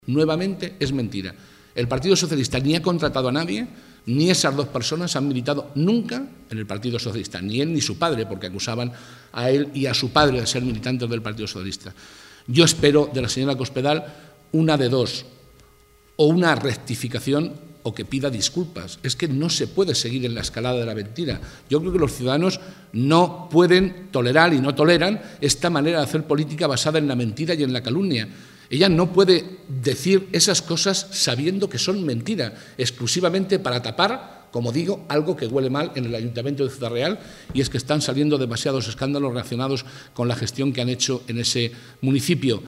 El portavoz del Grupo Parlamentario Socialista en las Cortes de Castilla-La Mancha, José Molina, ha puesto en evidencia “la nueva mentira de De Cospedal, pues ni el PSOE ha contratado a nadie para espiar a cargos del PP, ni el alto funcionario del Ayuntamiento de Ciudad Real, que inició la investigación, es militante socialista, ni lo es tampoco su padre, al que también se acusaba. De hecho, estas dos personas no han militado nunca en el PSOE”.
Cortes de audio de la rueda de prensa